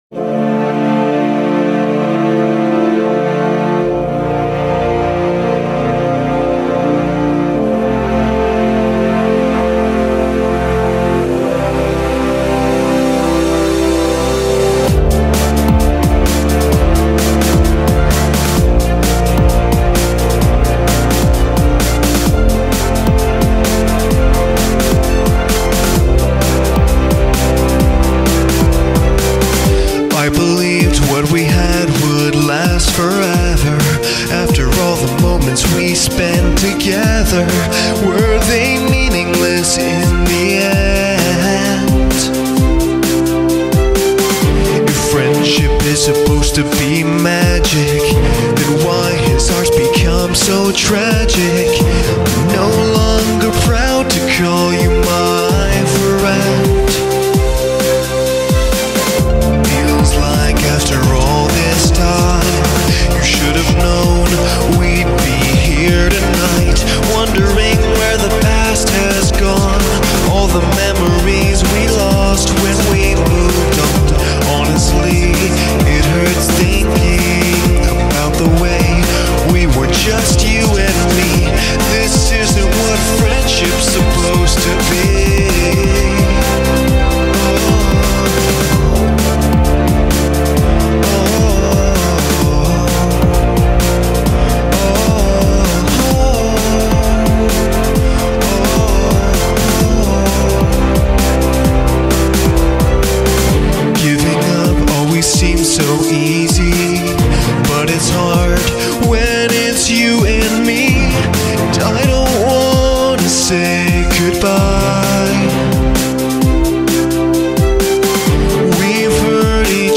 It's a sad song, but happy at the same time.